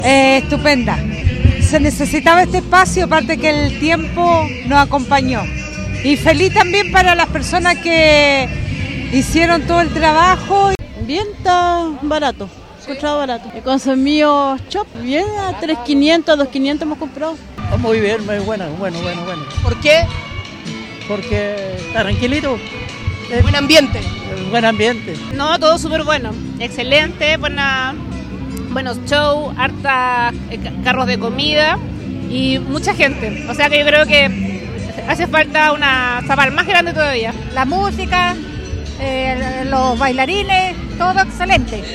Radio Bío Bío llegó al lugar para conocer las impresiones en los instantes finales de los festejos.